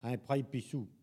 Collectif-Patois (atlas linguistique n°52)